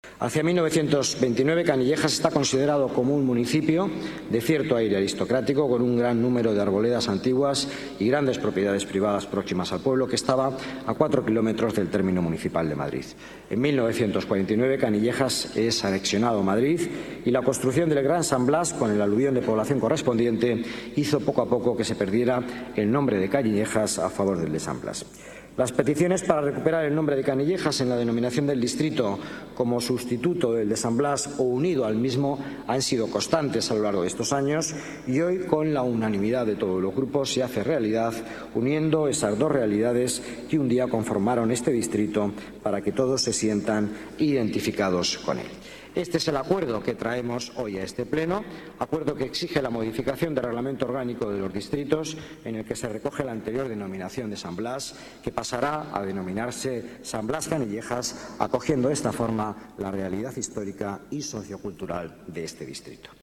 Nueva ventana:Declaraciones de Miguel Ángel Villanueva, vicealcalde de Madrid